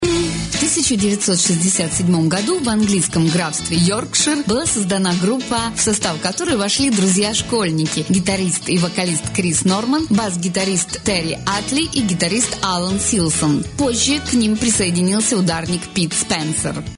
После подачи воздуха инструмент издаёт звук, отдалённо напоминающий аккордеон.